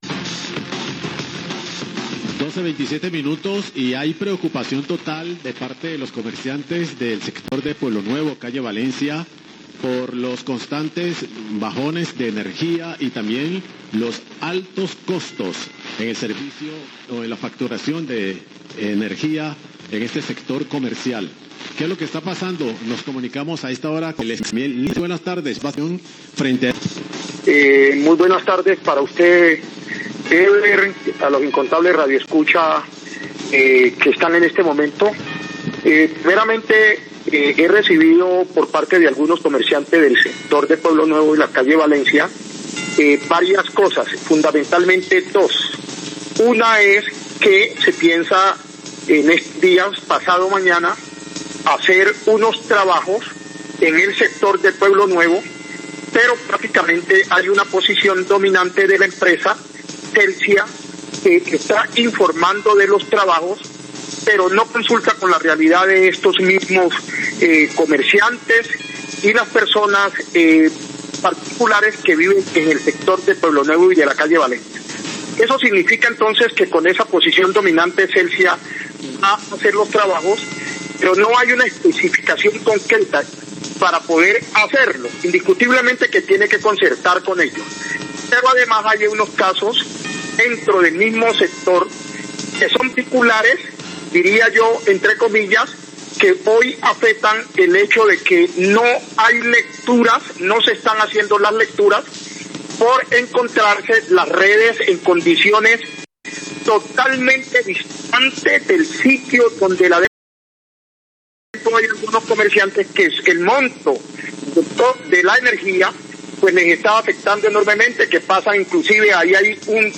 Radio
Ex concejal del distrito de Buenaventura Humberto Hurtado, habla de la inconformidad de los comerciantes del sector de Pueblo Nuevo referente al cobro elevado del servicio de energía el cual no está tomando las medidas.